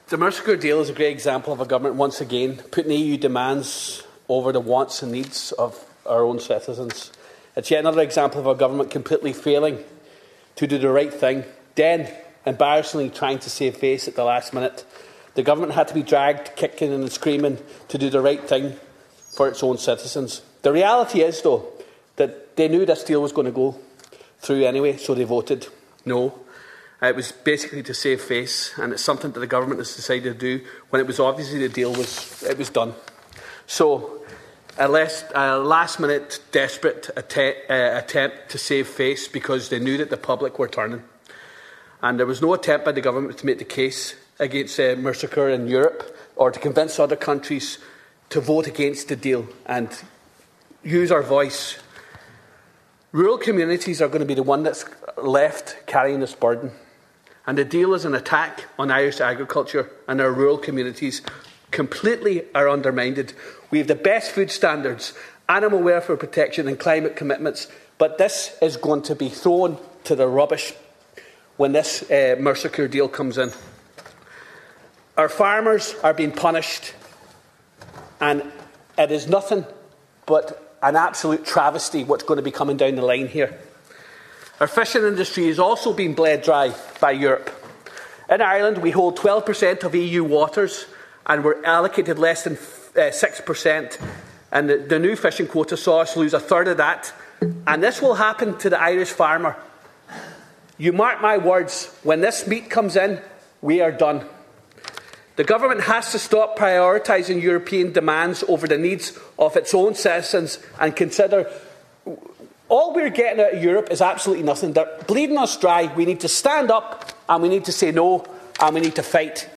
Deputy Charles Ward was speaking in the Dail during the debate on a Sinn Fein motion calling on the government to ask its MEPs to continue fighting the EU – Mercosur Agreement.